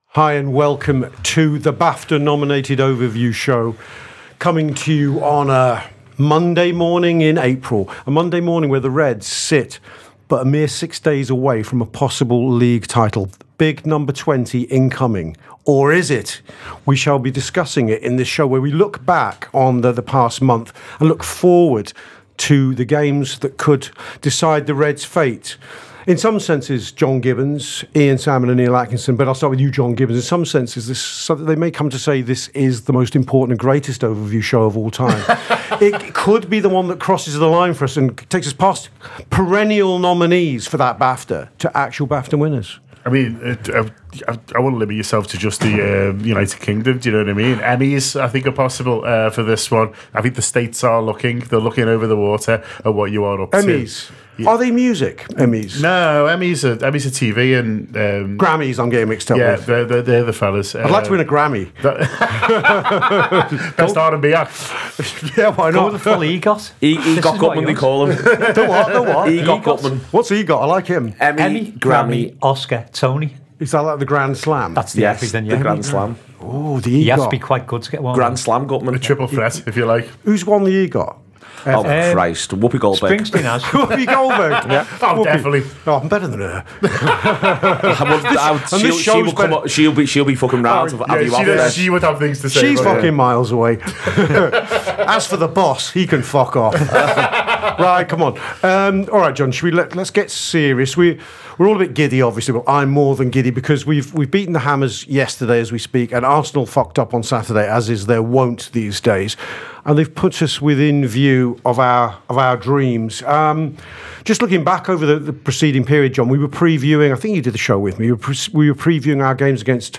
Below is a clip from the show – subscribe for more on Liverpool’s season